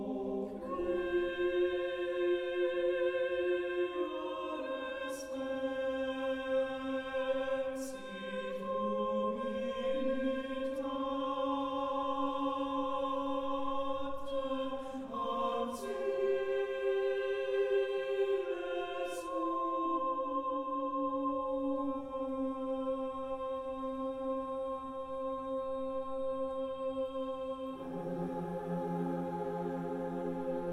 0 => "Musique vocale sacrée"